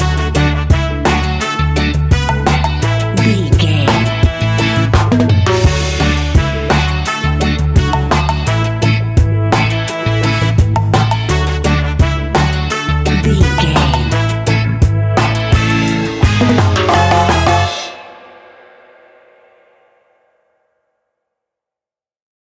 Classic reggae music with that skank bounce reggae feeling.
Uplifting
Aeolian/Minor
F#
laid back
off beat
drums
skank guitar
hammond organ
percussion
horns